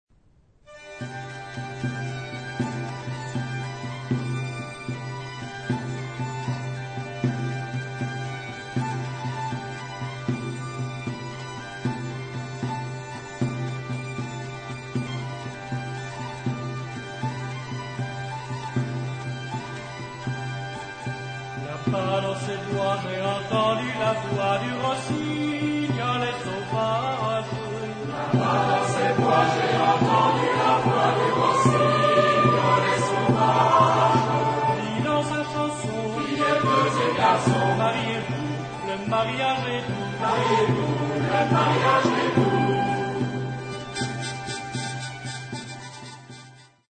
Género/Estilo/Forma: Profano ; Popular
Carácter de la pieza : simple ; energico
Tipo de formación coral: SATB  (4 voces Coro mixto )
Solistas : Soprano (1) / Ténor (1)  (2 solista(s) )
Tonalidad : la mayor
Origen: Delfinado (F)